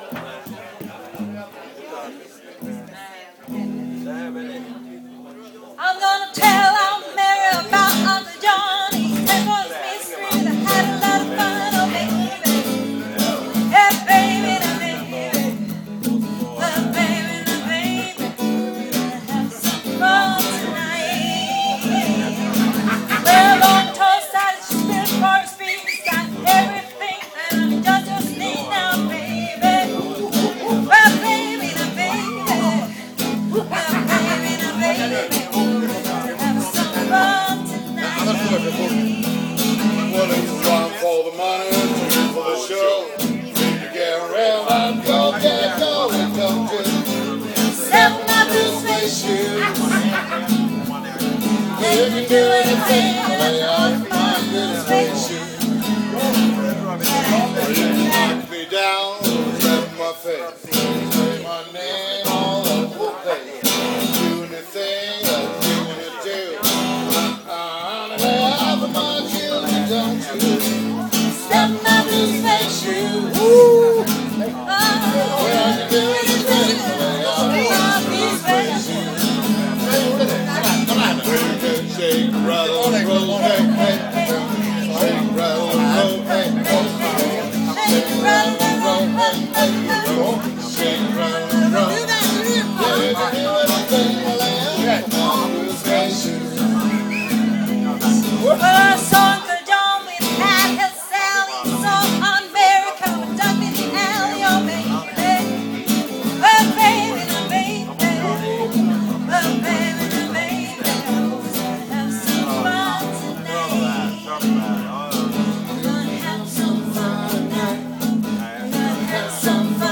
• Trubadur